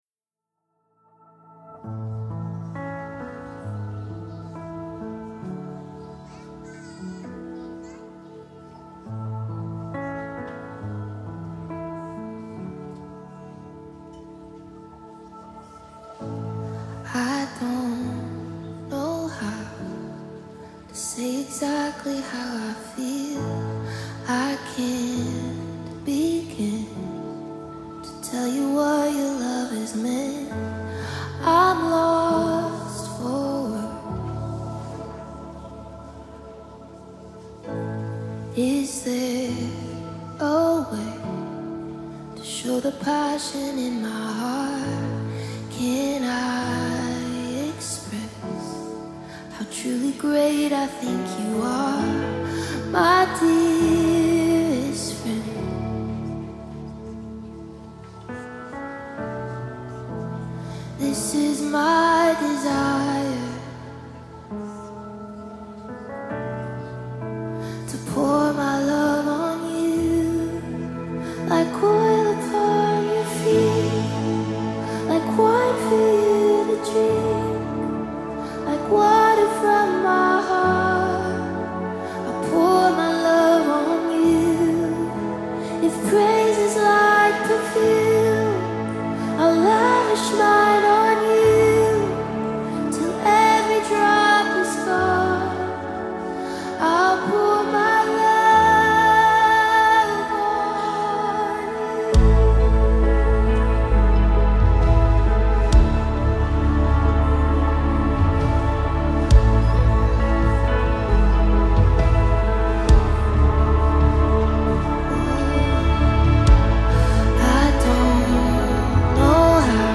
BPM: 67